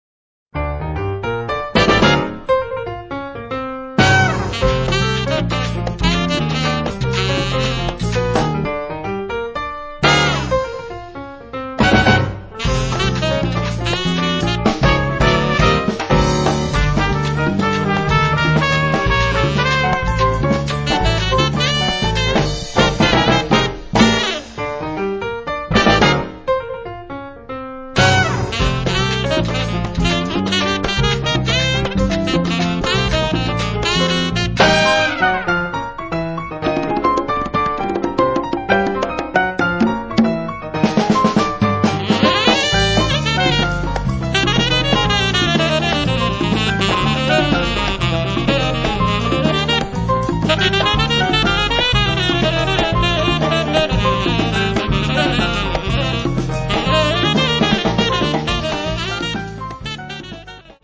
quasi reinventato in stile mambo